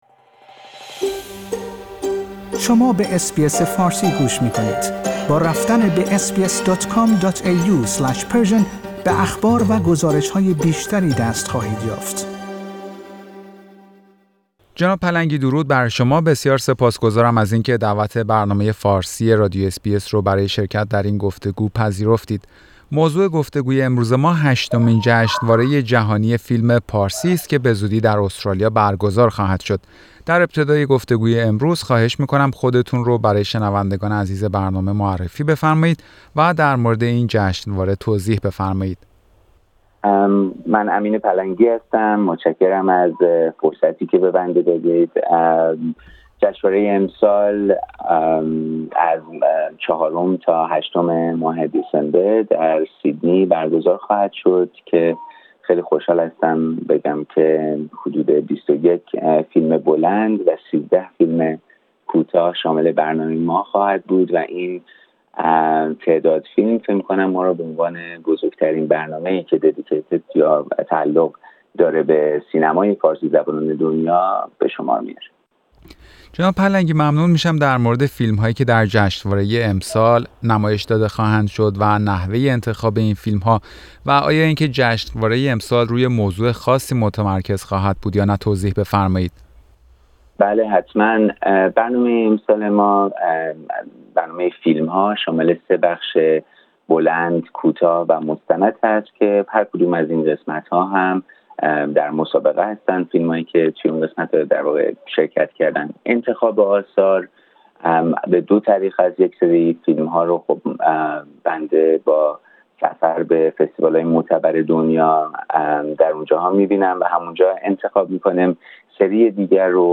برنامه فارسی رادیو اس بی اس در همین خصوص گفتگویی داشته